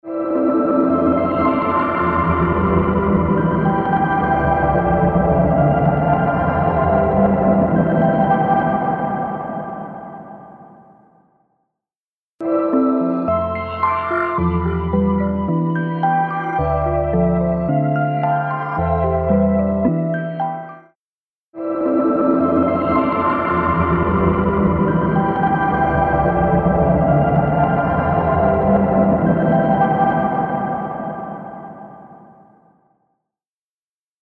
H949 Harmonizer | Lo-Fi Track | Preset: Nightmares (Dual)
ステレオの拡張、ダブリング、リバース・ピッチシフト用途